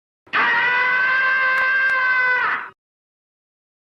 deathsound.mp3